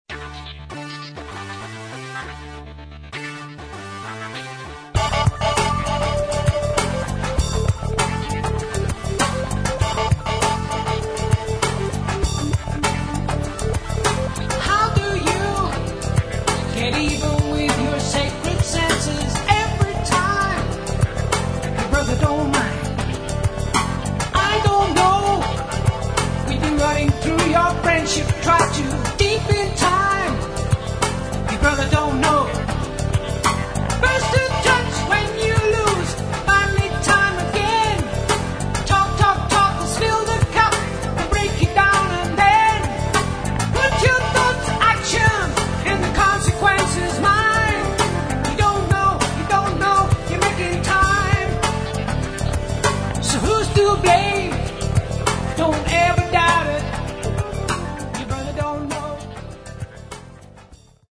Рок
На альбоме два вокала
В любом случае, качество записи на высоте.